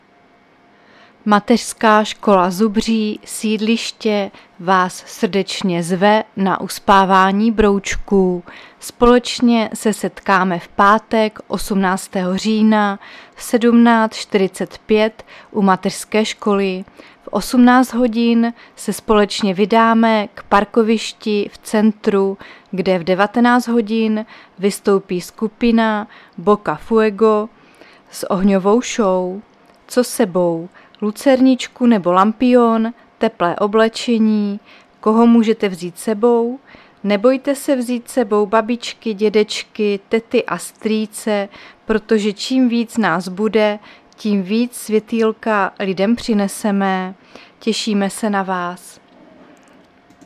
Záznam hlášení místního rozhlasu 16.10.2024
Zařazení: Rozhlas